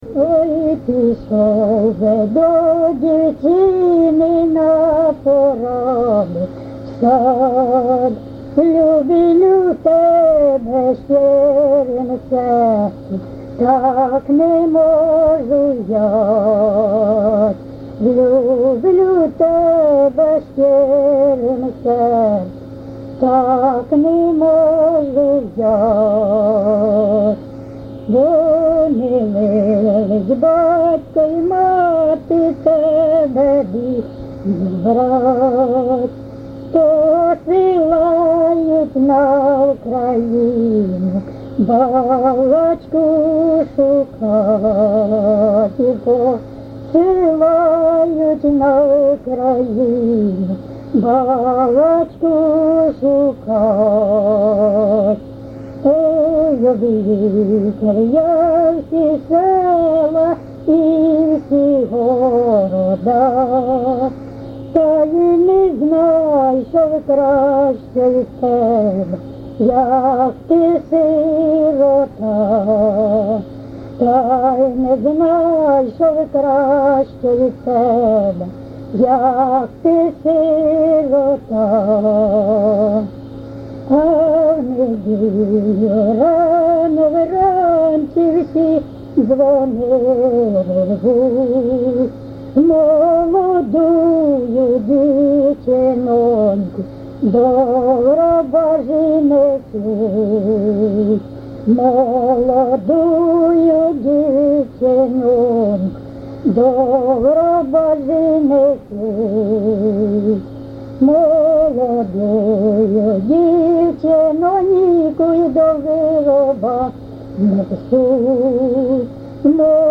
ЖанрПісні з особистого та родинного життя, Козацькі, Балади
Місце записус. Коржі, Роменський район, Сумська обл., Україна, Слобожанщина